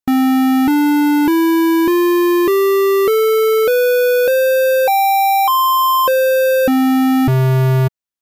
対称矩形波（Square）となります。